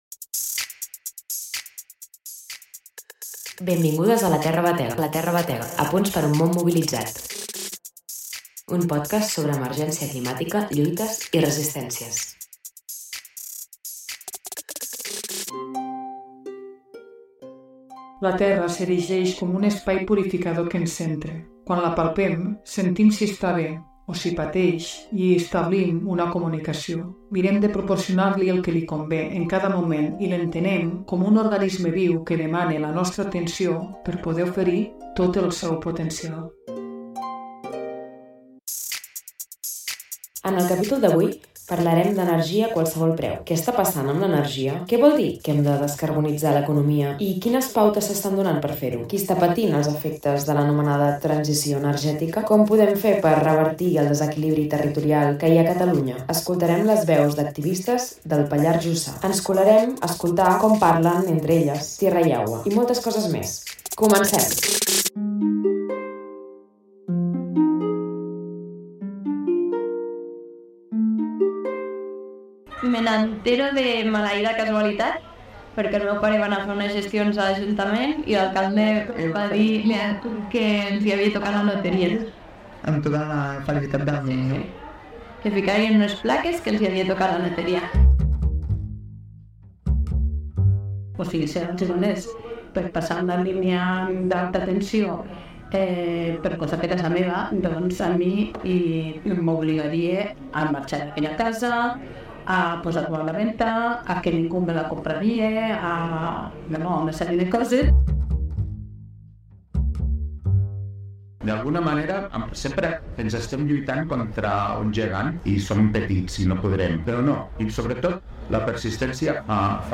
Qui està patint els efectes de l'anomenada transició energètica? Com podem fer per revertir el desequilibri territorial que hi ha a Catalunya? Escoltarem les veus d'activistes del Pallars Jussà i ens colarem a escoltar com parlen entre elles "tierra y agua", i moltes coses més!